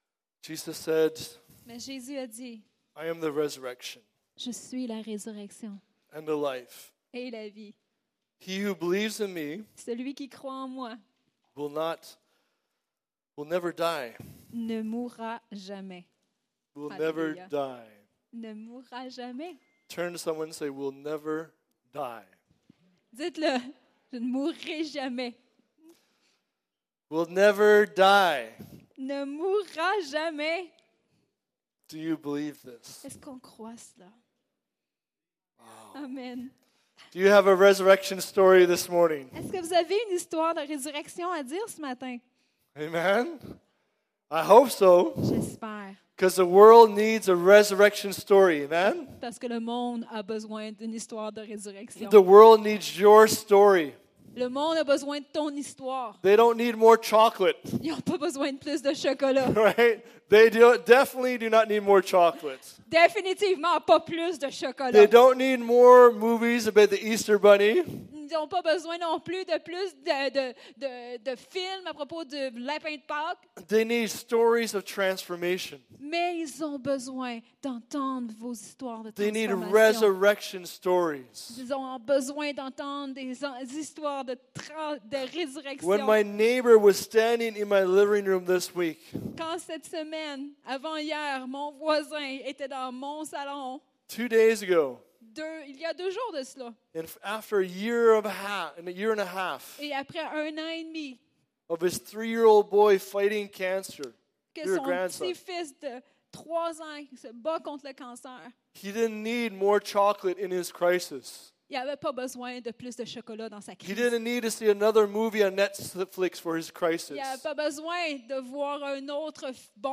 Sermons | Evangel Pentecostal Church